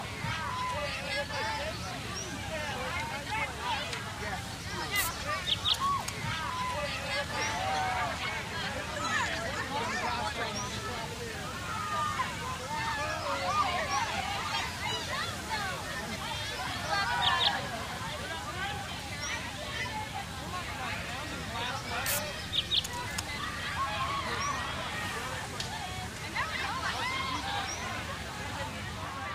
12. Вечеринка на природе, майовка по нашему